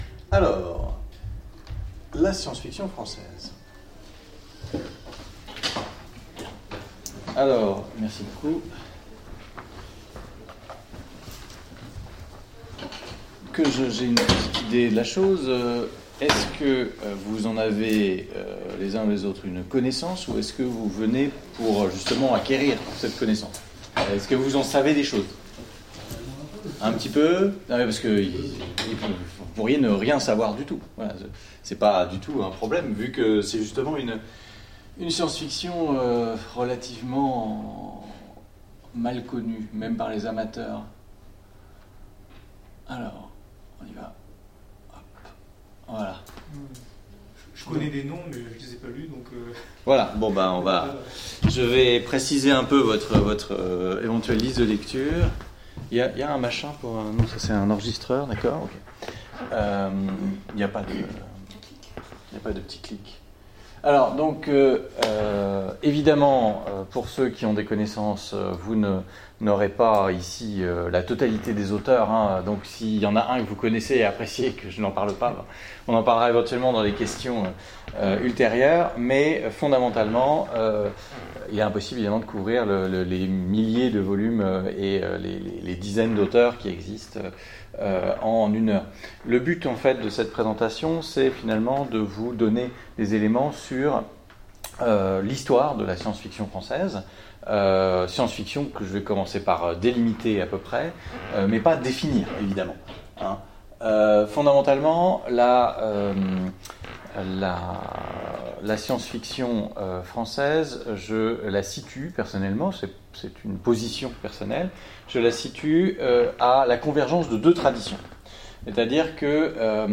Mots-clés SF Conférence Partager cet article